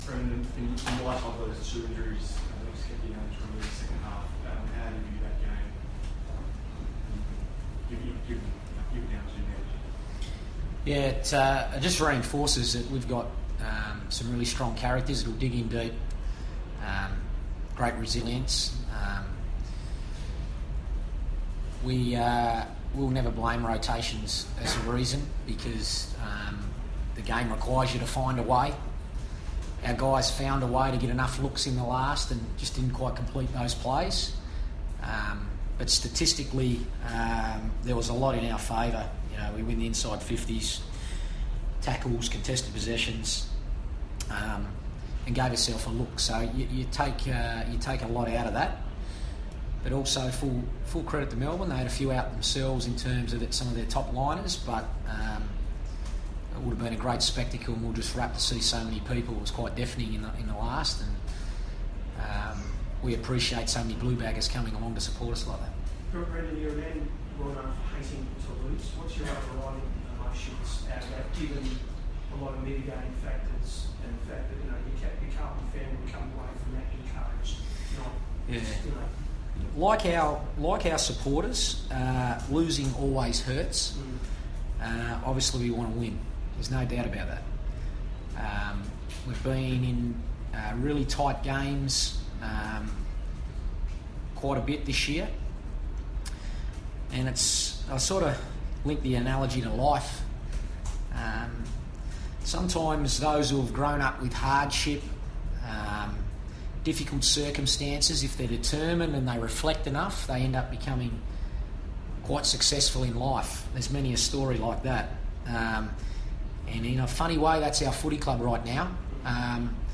Carlton coach Brendon Bolton fronts the media after the Blues' eight-point loss to Melbourne.